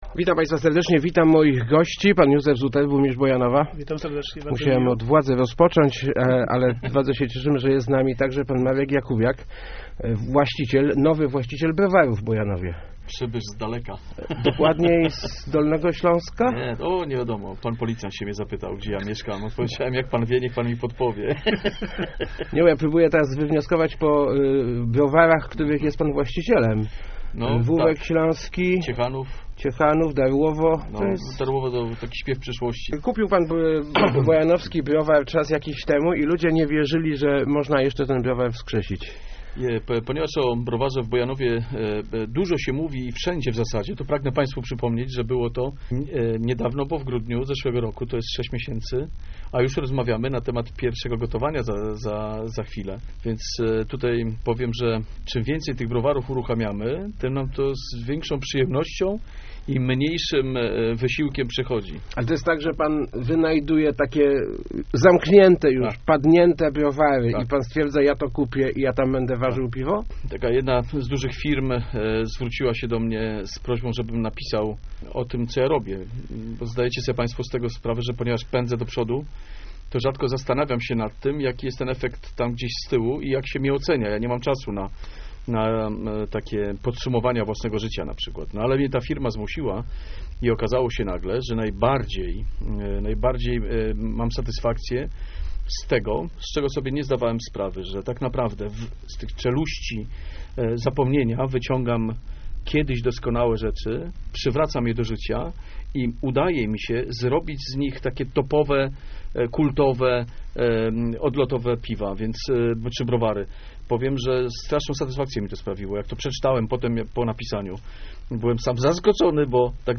Około 20 lipca rozpocznie się warzenie piwa w browarze w Bojanowie. Gotowe będzie 35 dni później - mówił w Rozmowach Elki Marek Jakubiak, właściciel browaru. -Czekaliśmy na to osiem lat - dodał burmistrz Bojanowa Józef Zuter.